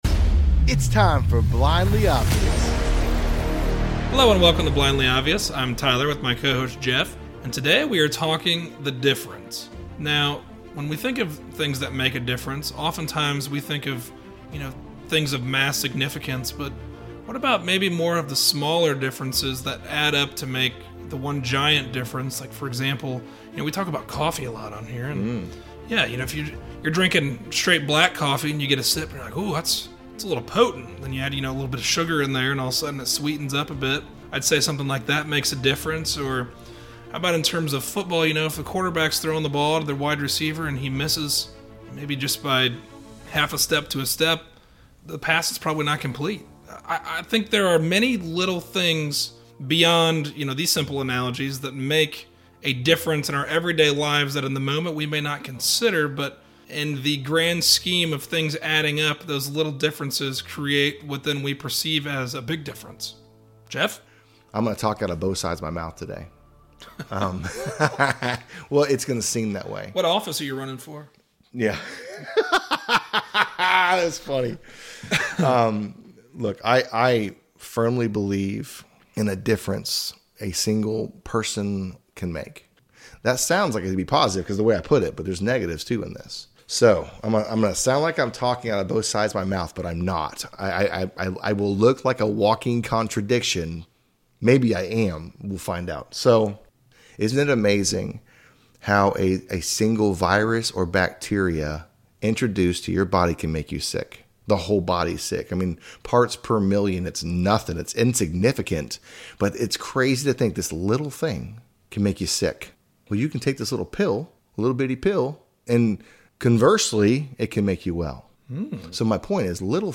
A conversation on making a difference. We all want to be part of things that have an immediate and significant impact, but is it the little things that add up to the big difference?